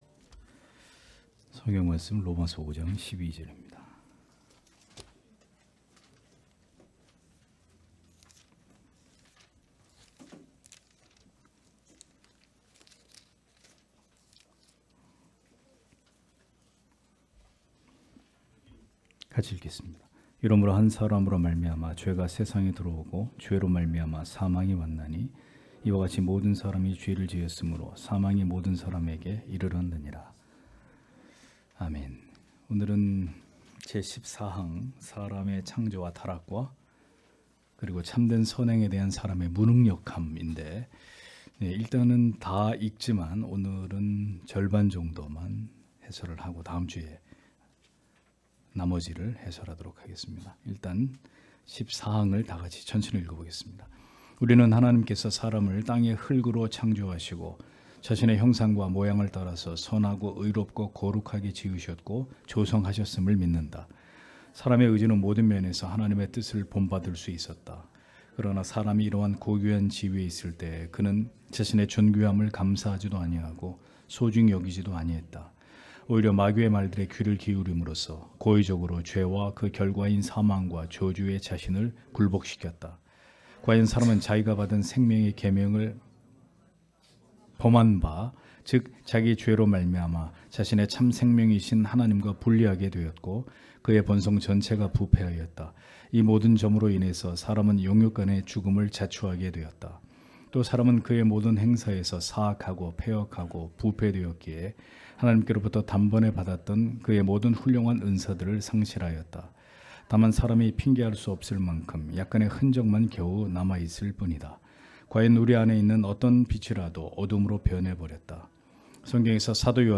주일오후예배 - [벨직 신앙고백서 해설 14] 제14항 사람의 창조와 타락과 그리고 참된 선행에 대한 사람의 무능력함 (롬 5장12절)